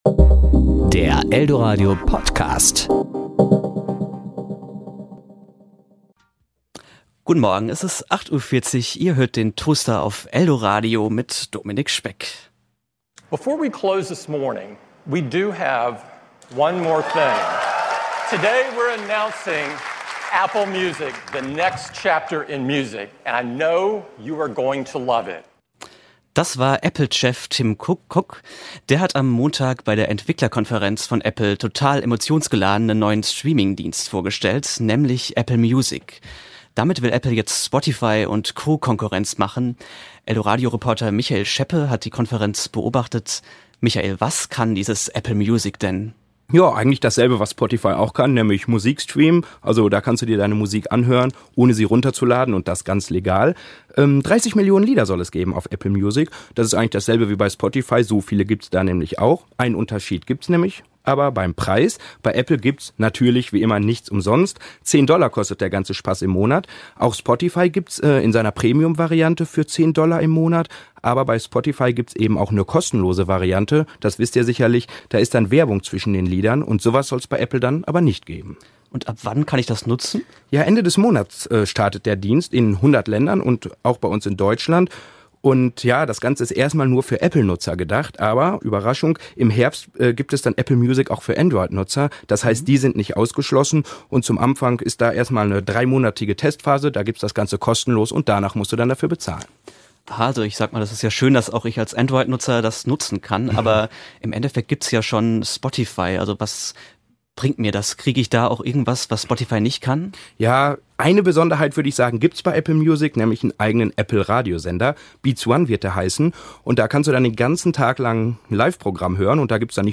Serie: Kollegengespräch
podcast_mitschnitt_kg_apple_music.mp3